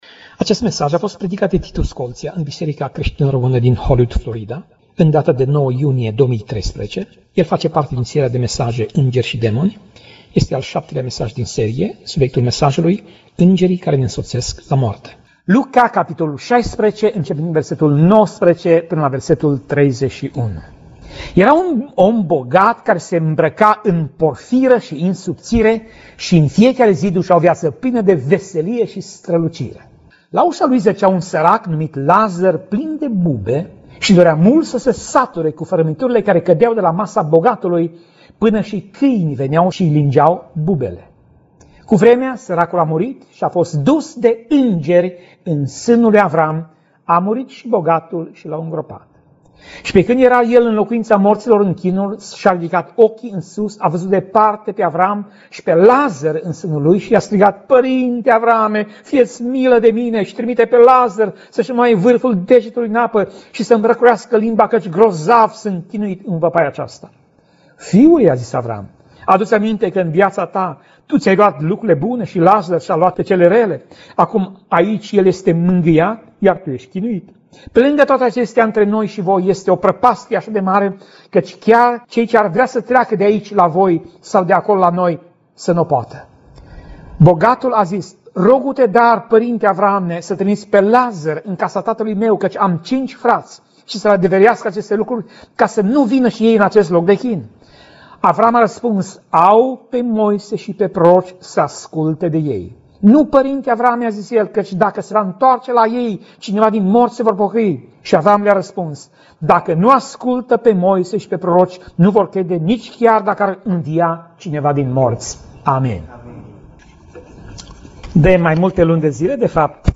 Pasaj Biblie: Luca 16:19 - Luca 16:31 Tip Mesaj: Predica